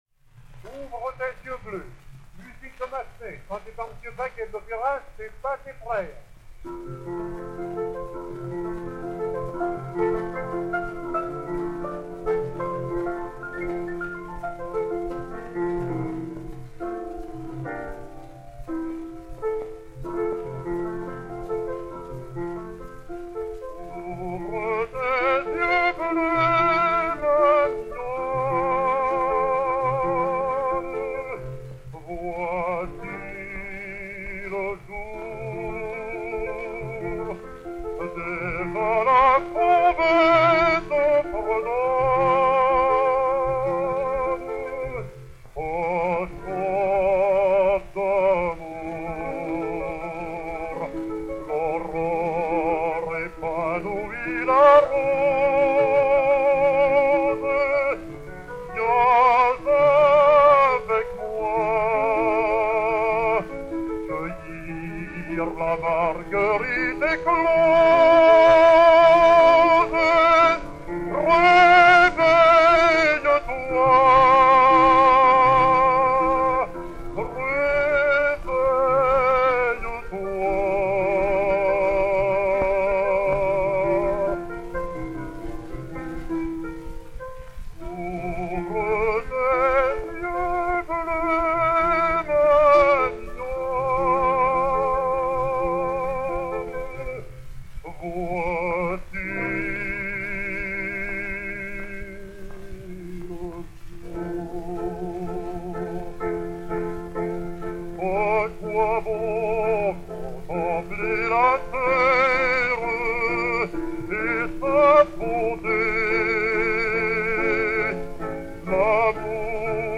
Albert Vaguet, ténor, avec piano